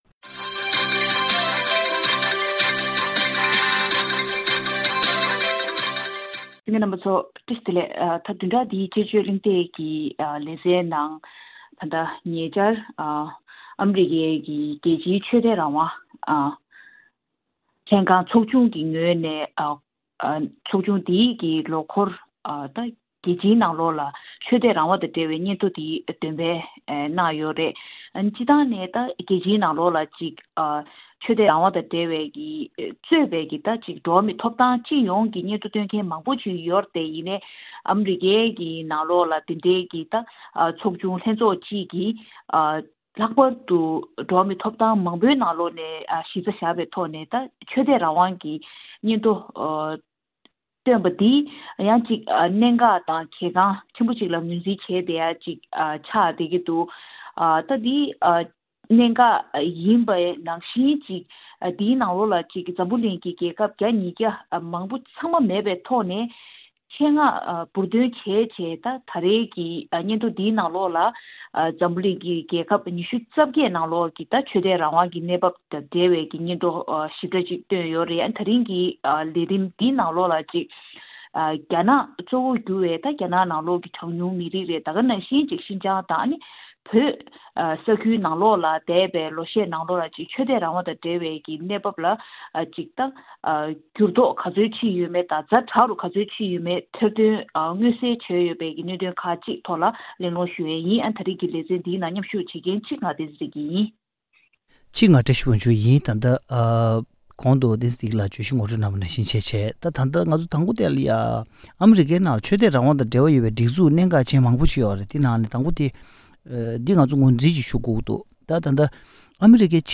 དཔྱད་གཞིའི་གླེང་མོལ་ཞུས་པ་འདི་གསན་རོགས་གནང་།།